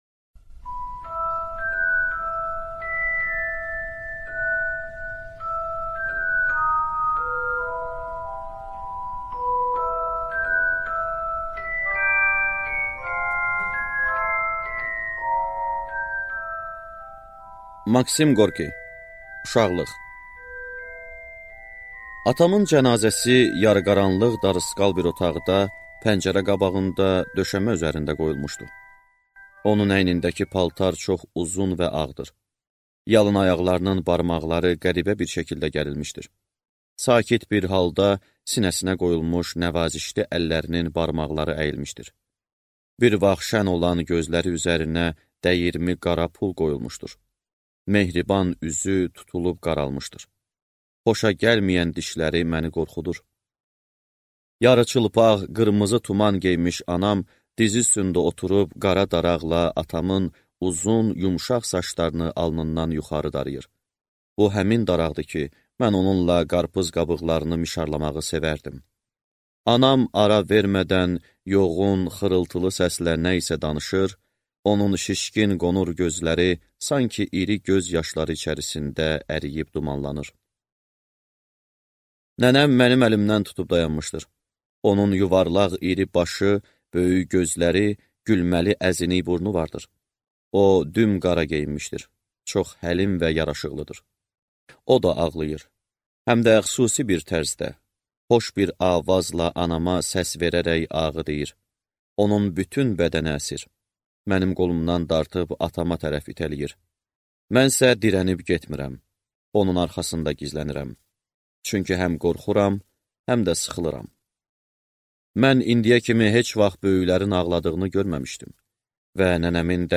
Аудиокнига Uşaqlıq | Библиотека аудиокниг